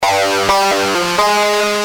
Lead_a3.wav